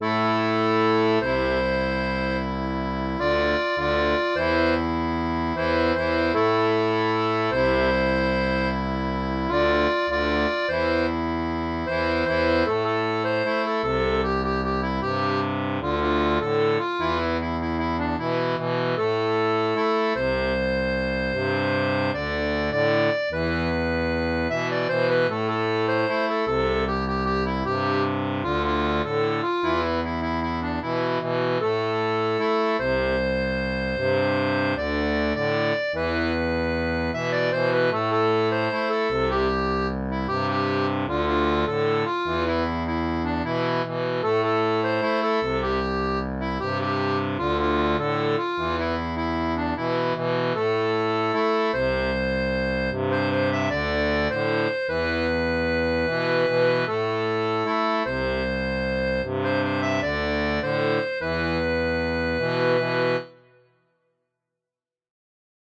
Musique cubaine